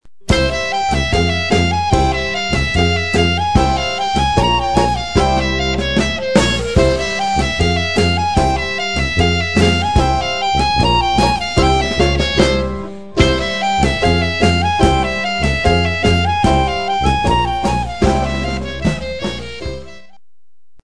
A virtuoso clarinet performance